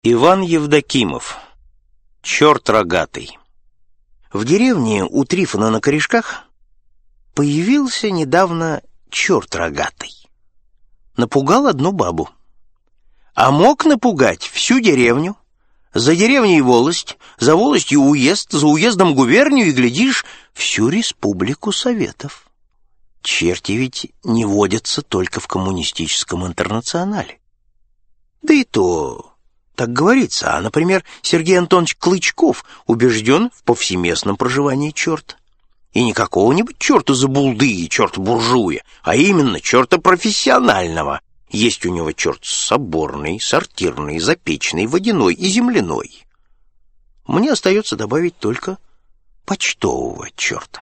Аудиокнига Классика русского рассказа № 2 | Библиотека аудиокниг
Aудиокнига Классика русского рассказа № 2 Автор Сборник Читает аудиокнигу Анатолий Кузнецов.